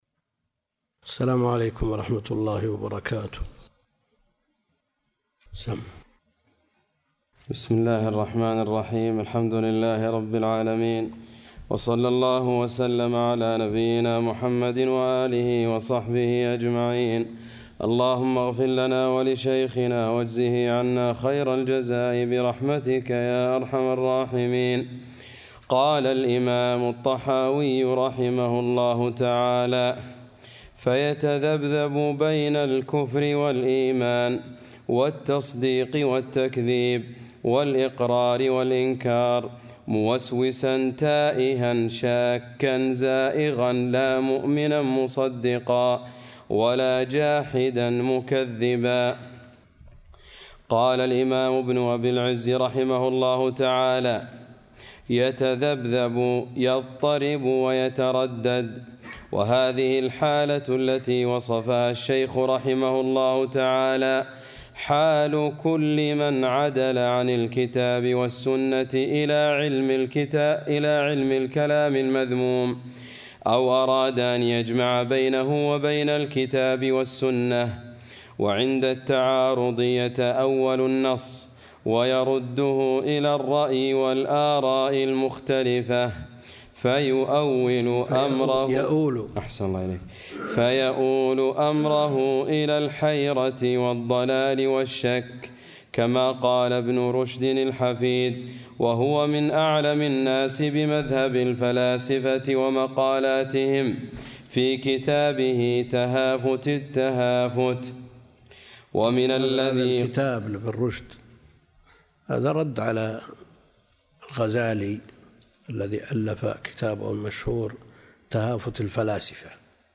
الدرس (27) شرح العقيدة الطحاوية - الدكتور عبد الكريم الخضير